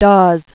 (dôz), Charles Gates 1865-1951.